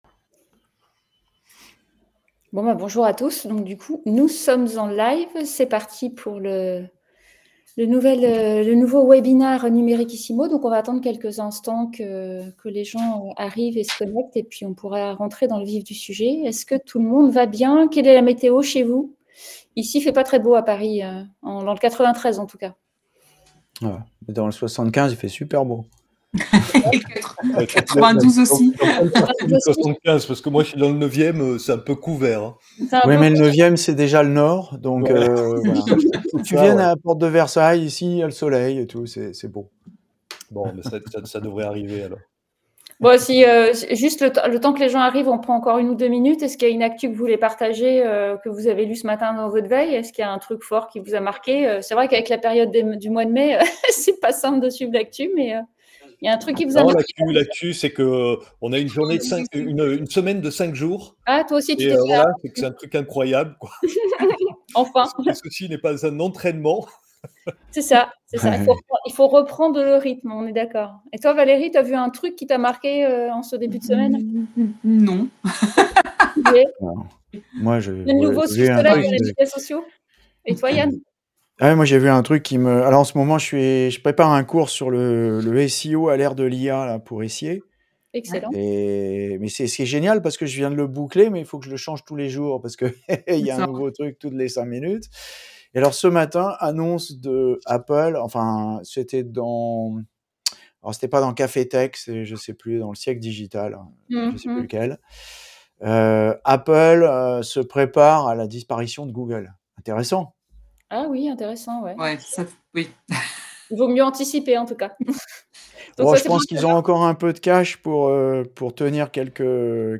Ce webinaire vous invite à repenser vos stratégies !